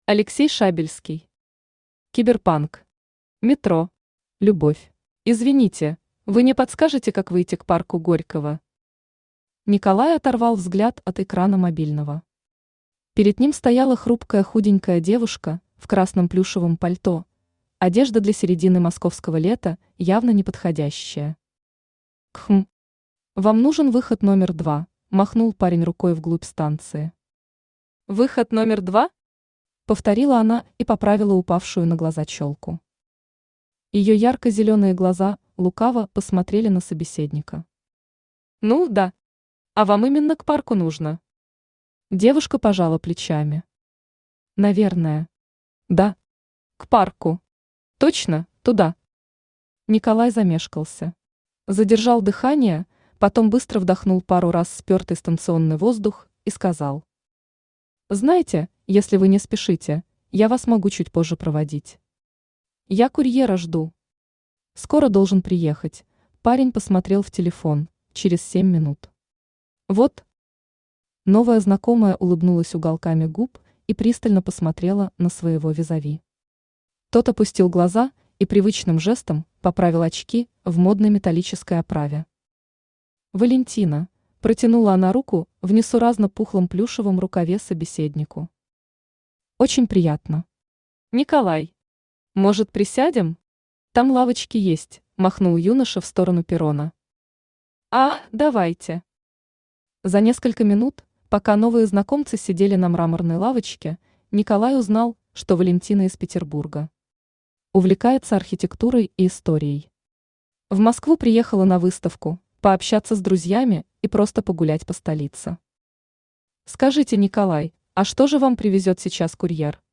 Аудиокнига Киберпанк. Метро. Любовь | Библиотека аудиокниг